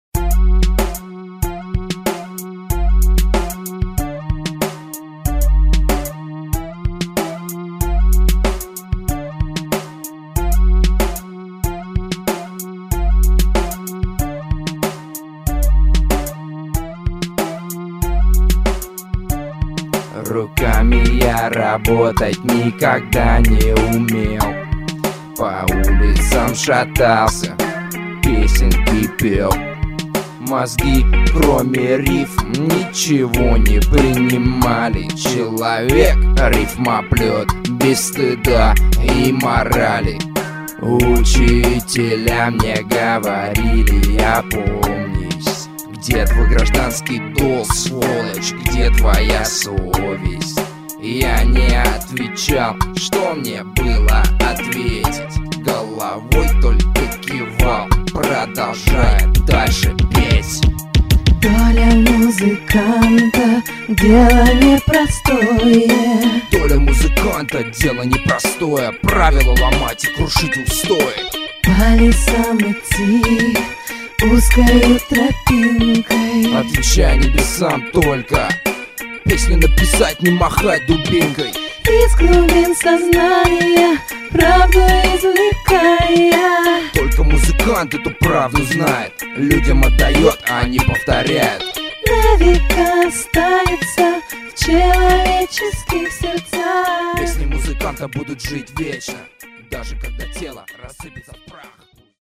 Все делает сам - пишет слова, музыку, сам записывает и исполняет.
Я занимаюсь русским рэпом.